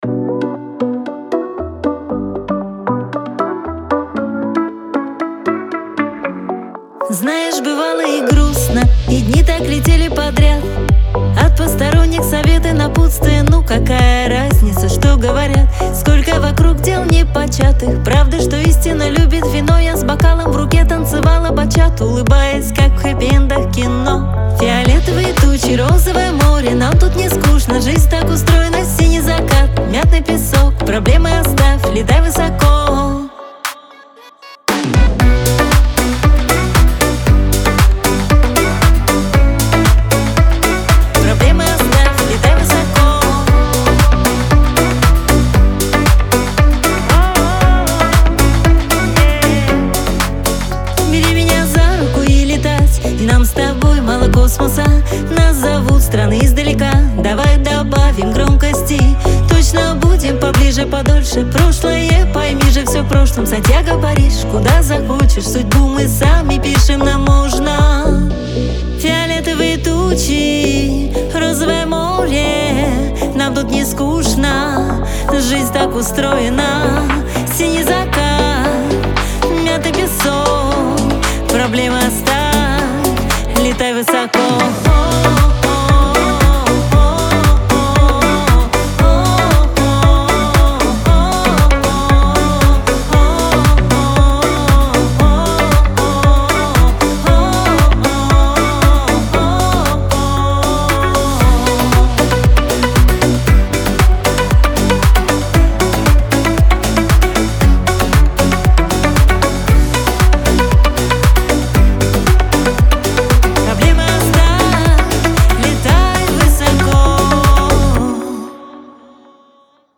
pop , Лирика